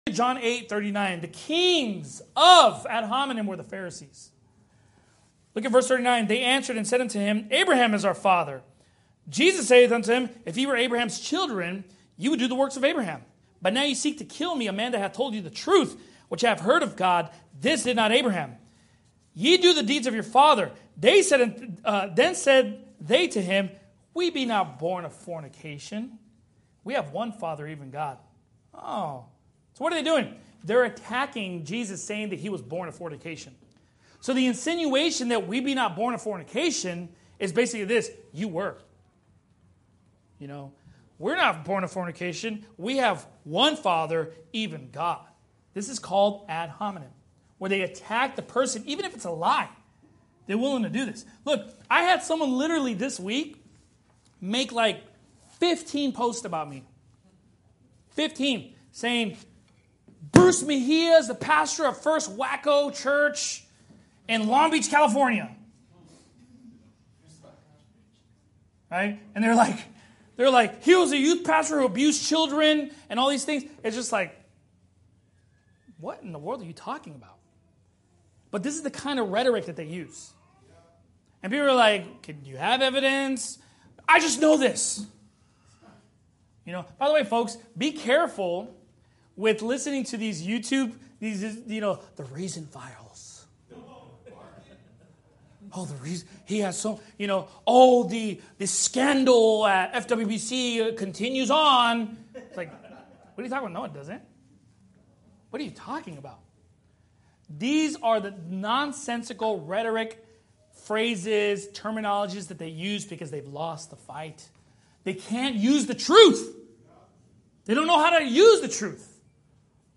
Every NIFB Sermon and More